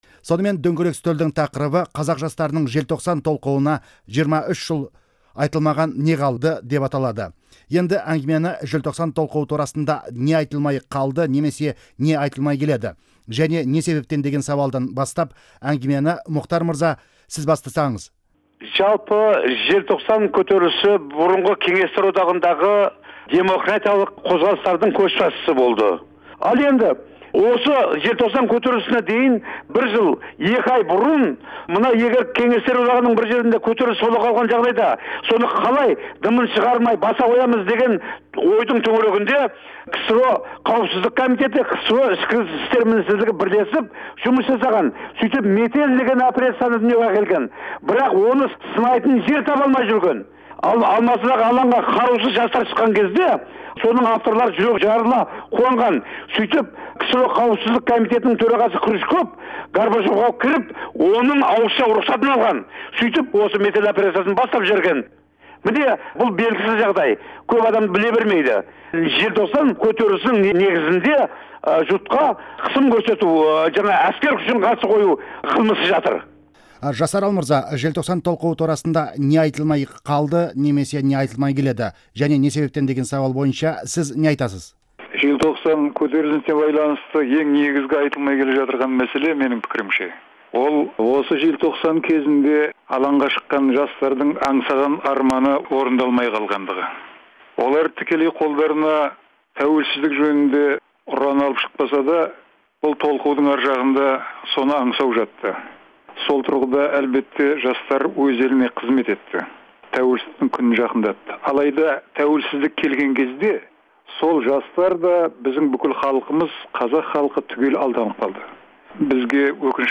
Желтоқсан тақырыбындағы дөңгелек үстел сұхбатын тыңдаңыз